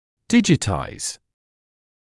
[‘dɪʤɪtaɪz][‘диджитайз]преобразовывать в цифровую форму, оцифровывать